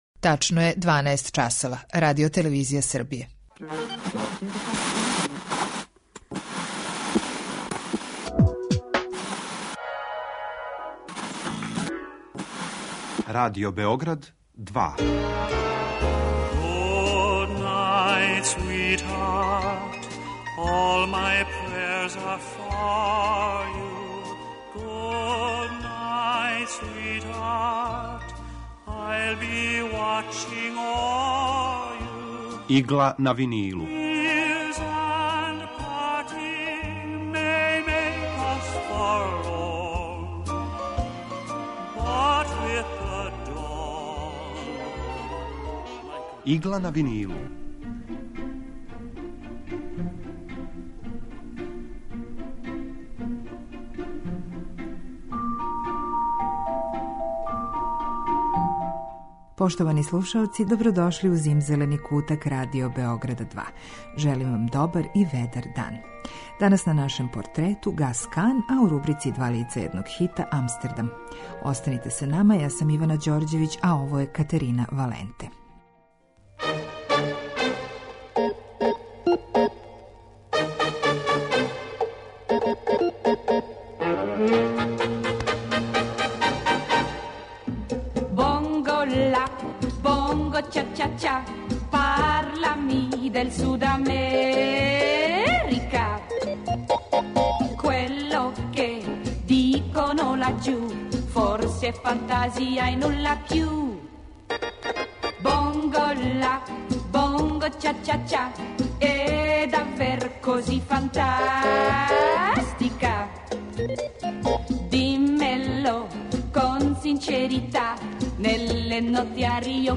Евергрин музика